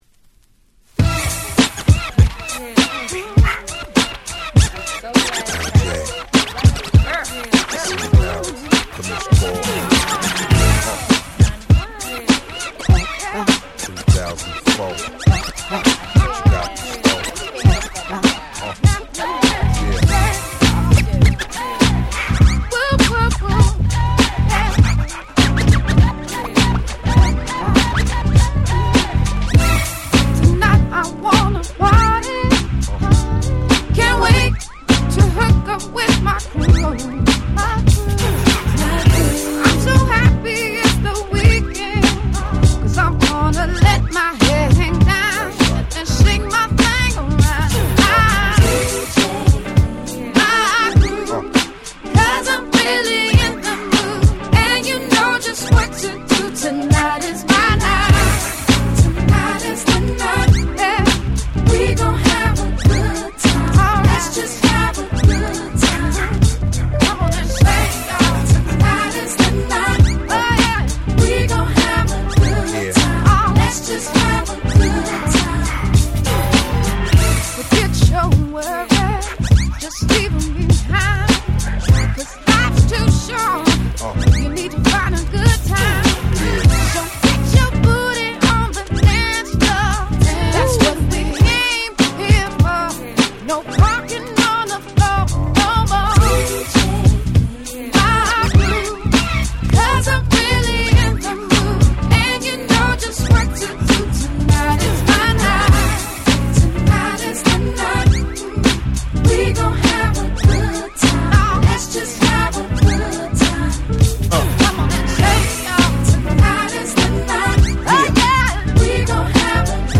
04’ Nice R&B !!
をサンプリングした軽快なDanceナンバー！！